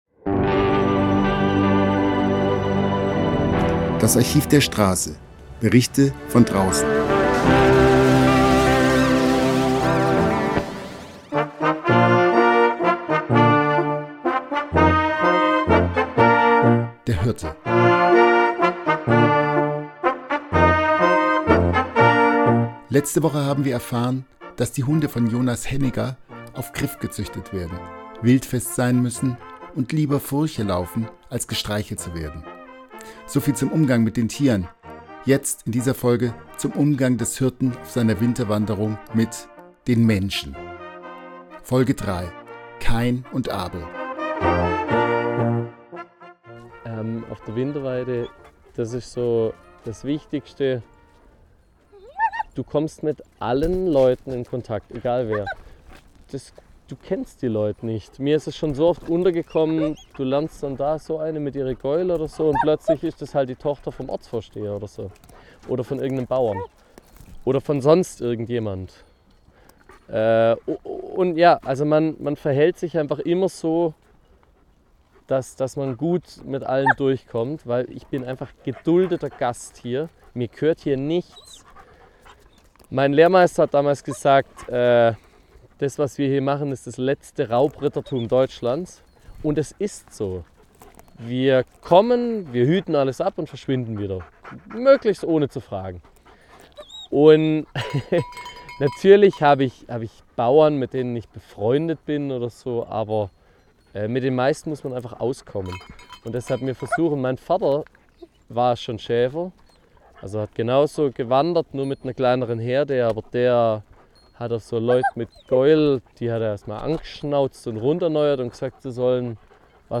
Musik: Bad Uracher Schäfermusik Mehr